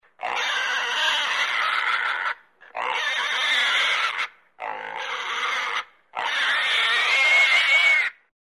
Звуки поросенка